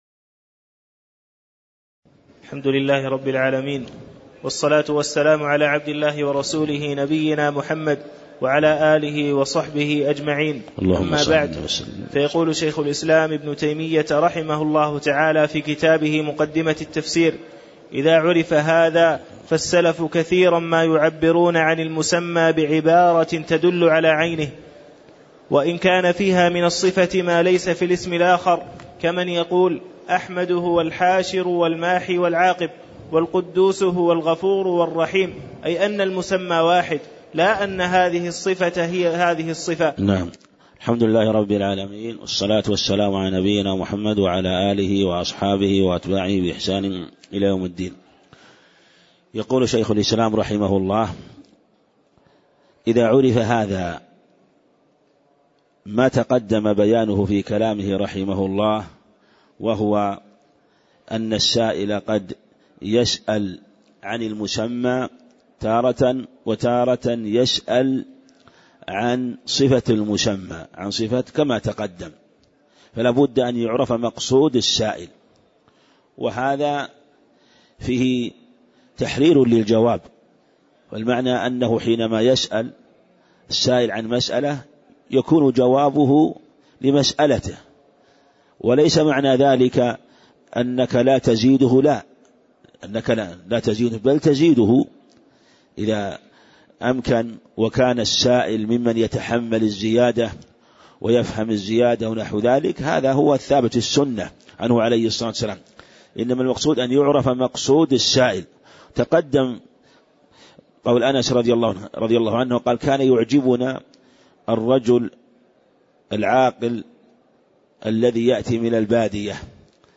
تاريخ النشر ١٦ شوال ١٤٣٨ هـ المكان: المسجد النبوي الشيخ